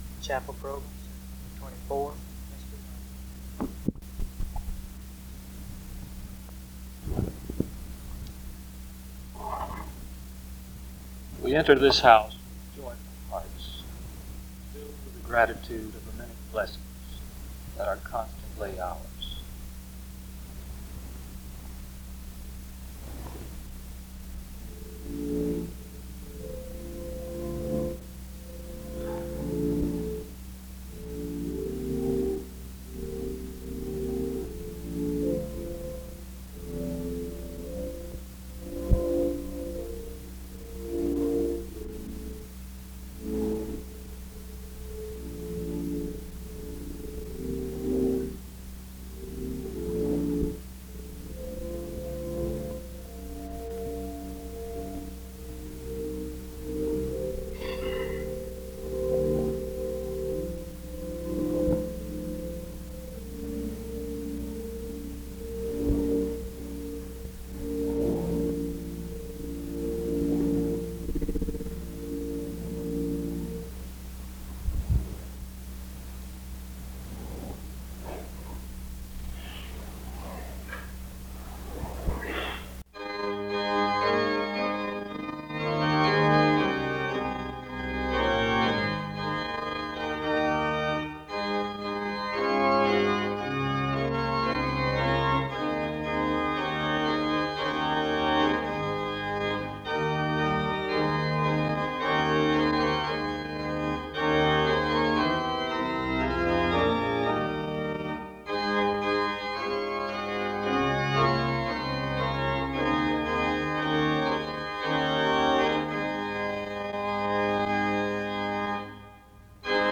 The service begins with music from 0:00-4:53. There is a responsive reading from 5:01-6:03. A prayer is offered from 6:14-8:37.
SEBTS Chapel and Special Event Recordings SEBTS Chapel and Special Event Recordings